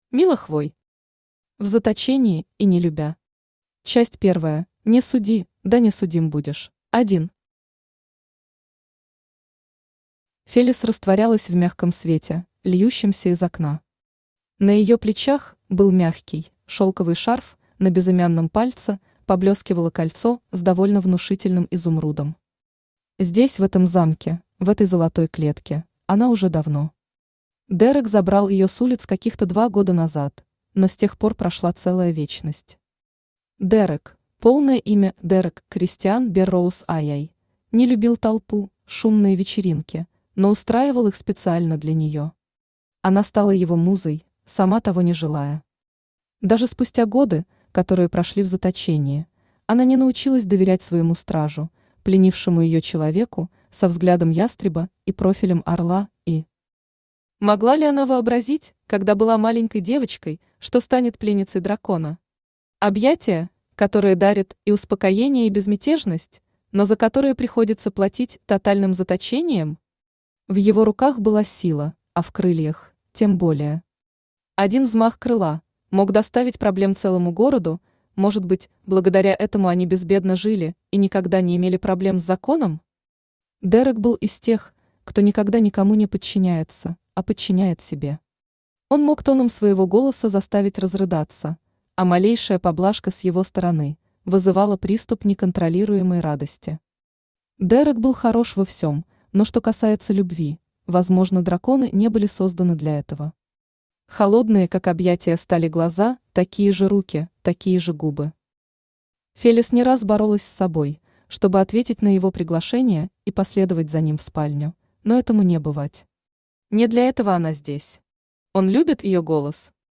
Аудиокнига В заточении и не любя! Часть первая: Не суди, да не судим будешь!
Автор Мила Хвой Читает аудиокнигу Авточтец ЛитРес.